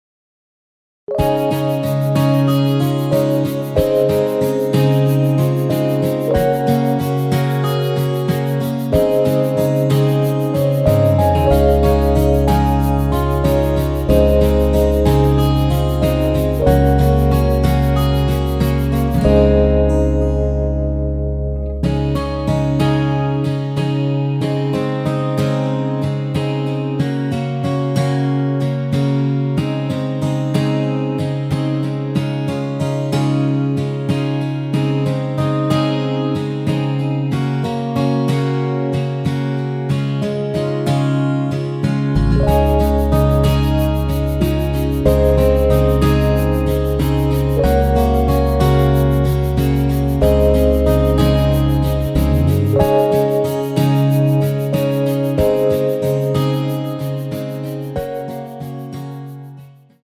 MR입니다.
Db
앞부분30초, 뒷부분30초씩 편집해서 올려 드리고 있습니다.
중간에 음이 끈어지고 다시 나오는 이유는